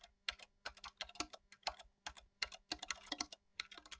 typing2.wav